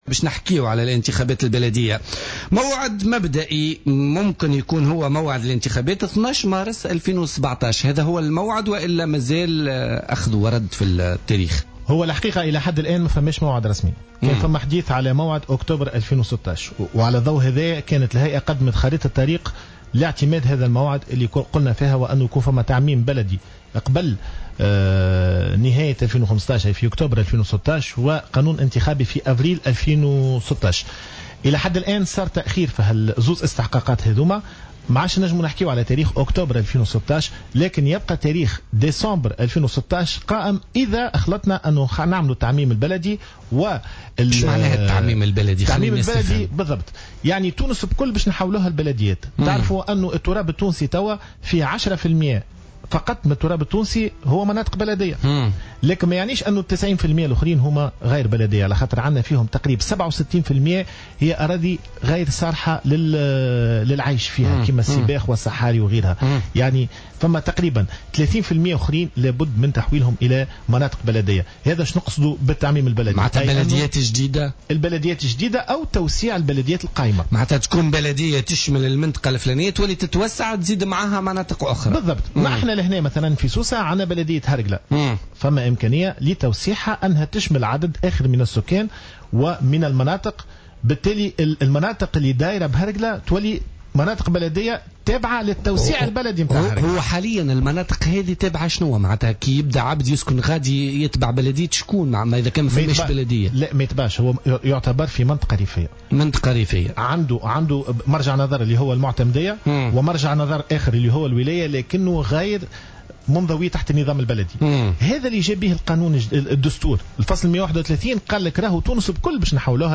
وأضاف بافون، ضيف برنامج "بوليتيكا" اليوم الثلاثاء أن إنهاء تقسيم الدوائر الانتخابية و تعميم البلديات على كامل تراب الجمهورية سيمكن هيئة الانتخابات من التعرف على كل الدوائر الانتخابية و تحديد الناخبين، وفق تعبيره.